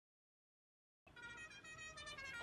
Horn Cucaracha Sound Button - Free Download & Play
Sound Effects Soundboard0 views